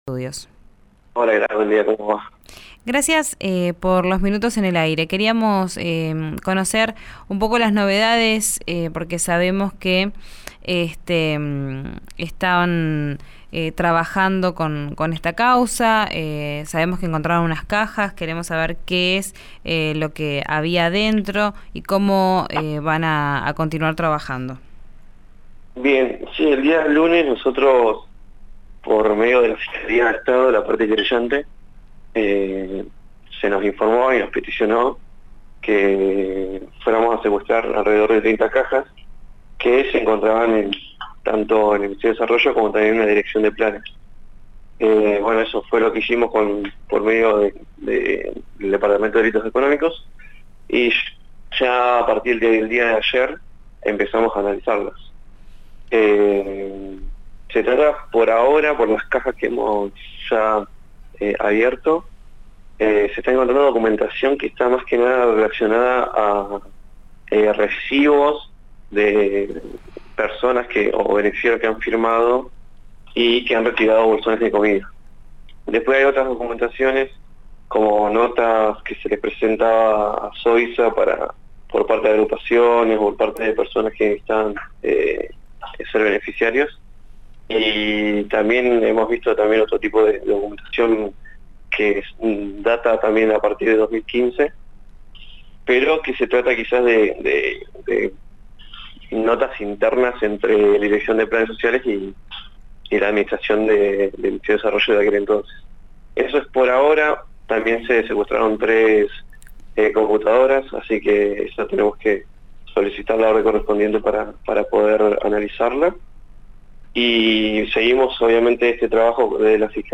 El fiscal de Delitos Económicos, Juan Manuel Narváez, contó los detalles en RÍO NEGRO RADIO. Escuchá la entrevista completa.